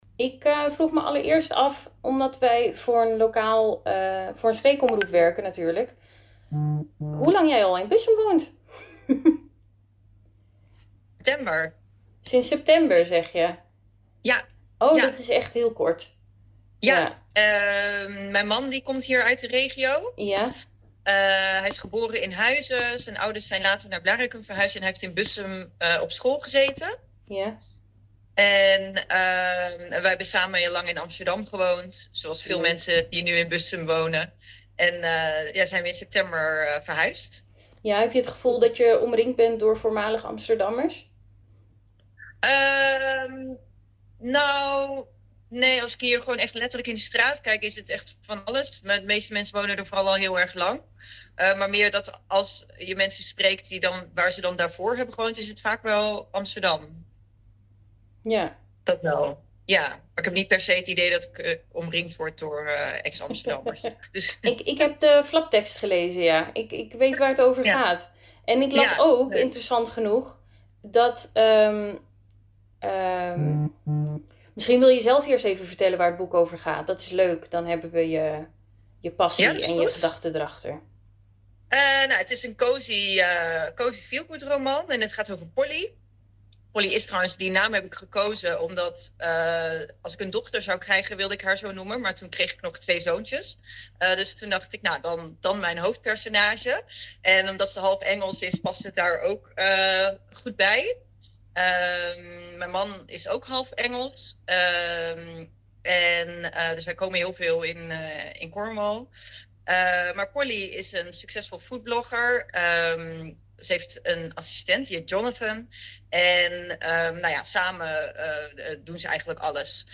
Podcast interview